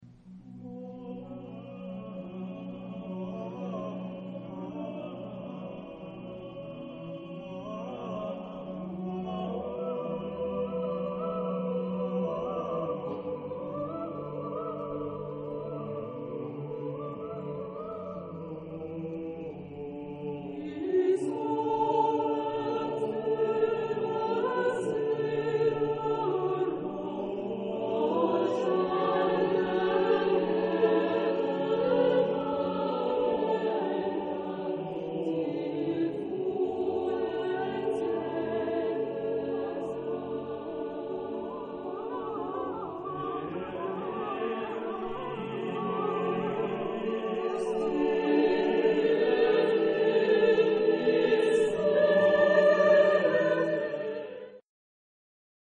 Type de choeur : SSAATB  (7 voix mixtes )
Tonalité : ré (centré autour de)